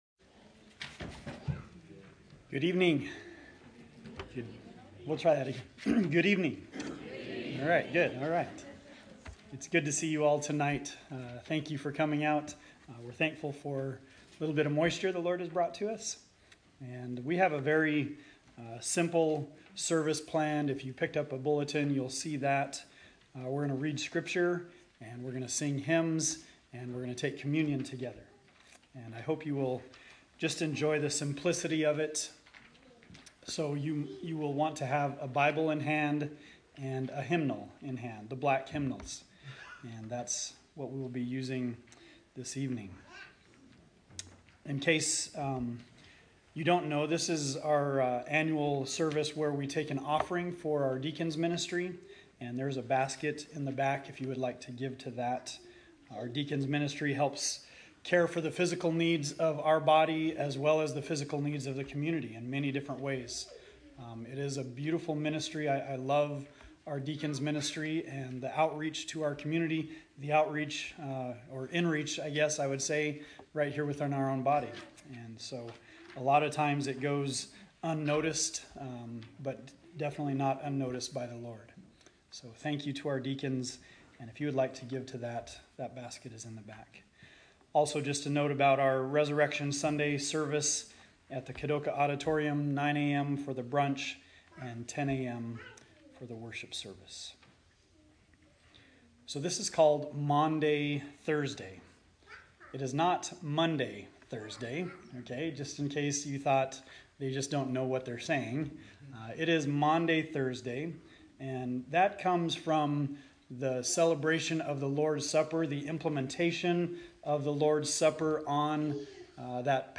Maunday Thursday Service